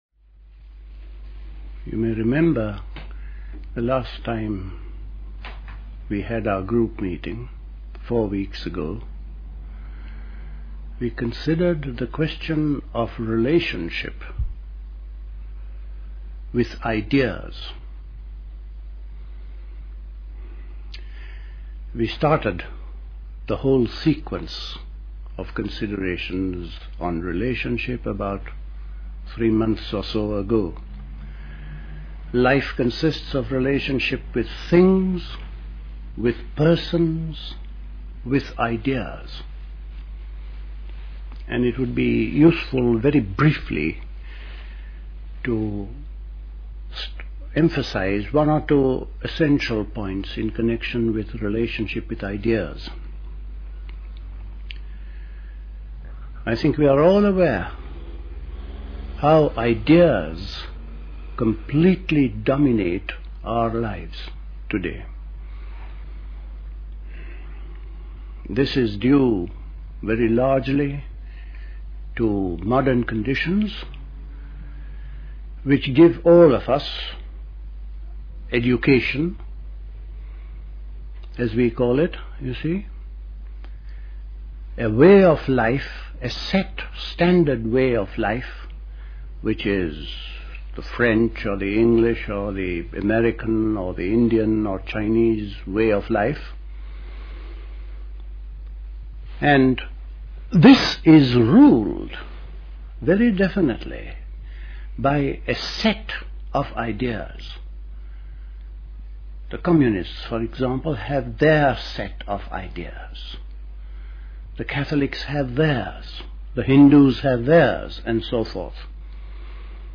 A talk given